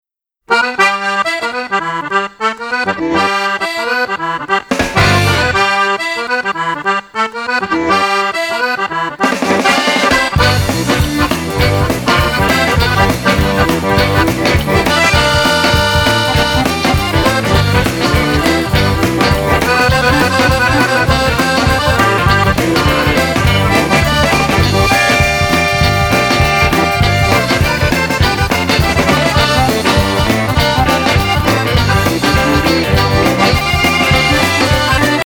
Genre: Country & Folk.